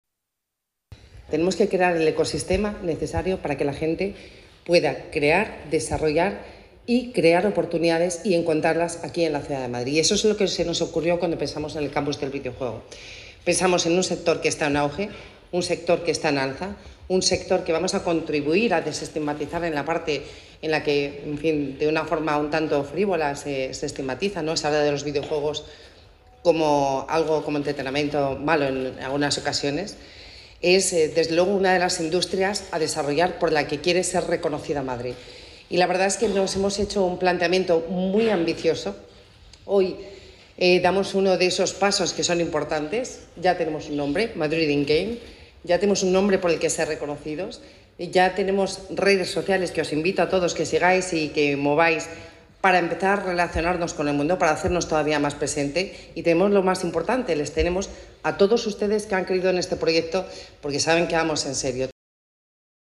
La vicealcaldesa de Madrid, Begoña Villacís, junto al delegado de Economía, Innovación y Empleo, Miguel Ángel Redondo, ha presentado en el Campus del Videojuego ‘Madrid In Game’, el proyecto municipal con el que se busca impulsar la industria del videojuego y convertir a la capital en referente mundial del sector.